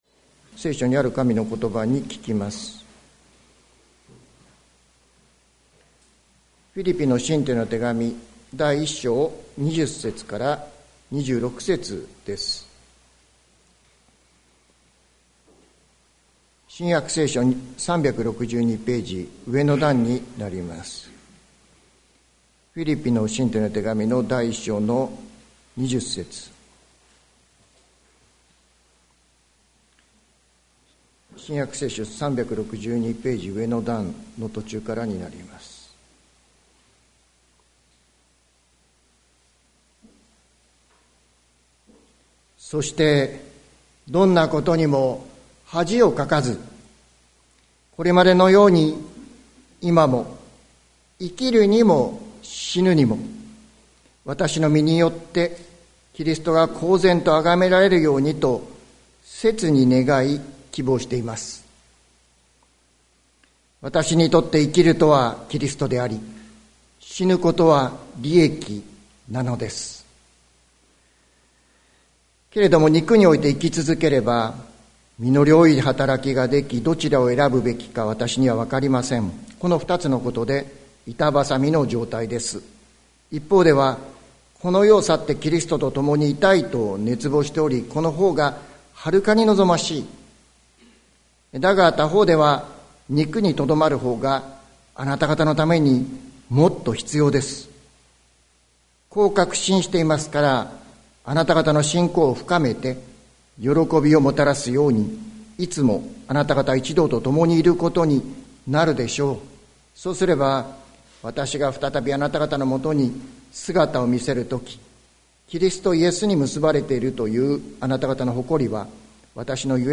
2024年04月28日朝の礼拝「キリストを大きくしよう」関キリスト教会
説教アーカイブ。